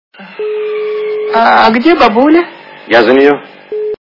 » Звуки » з фільмів та телепередач » Операция Ы и другие приключения Шурика - А где бабуля
При прослушивании Операция Ы и другие приключения Шурика - А где бабуля качество понижено и присутствуют гудки.